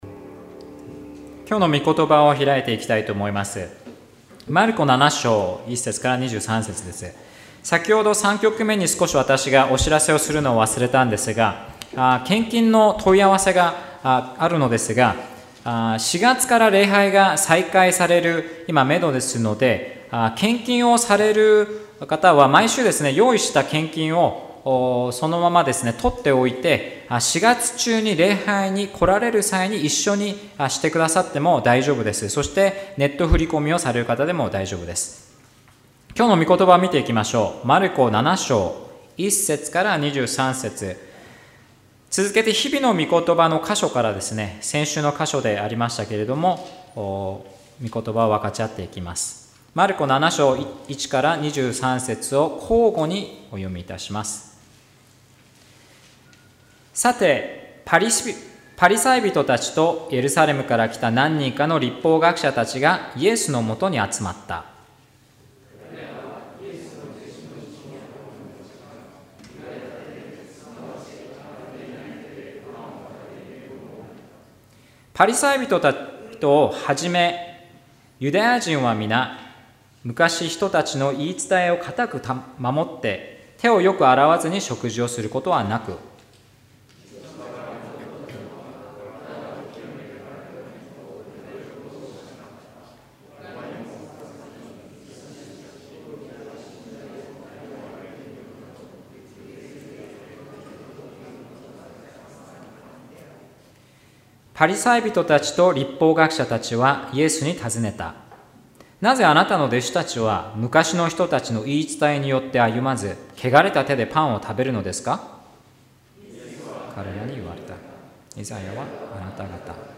説教